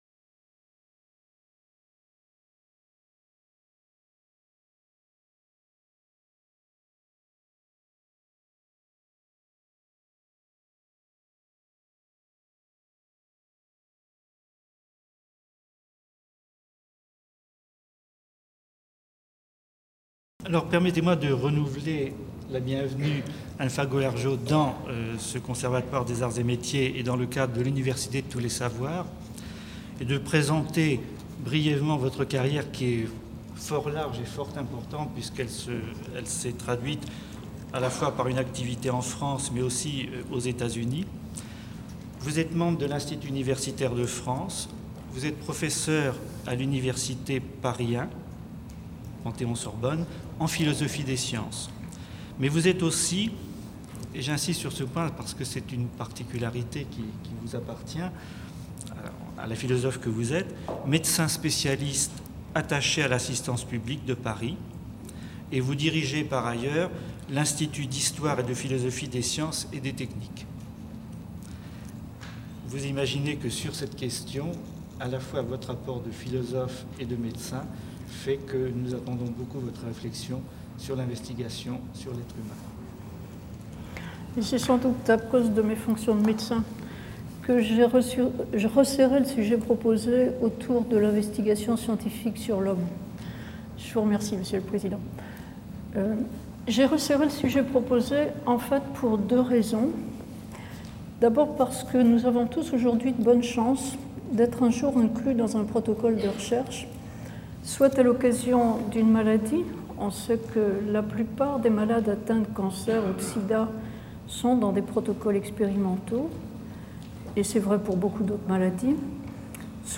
Conférence du 20 janvier 2000 par Anne Fagot-Largeault.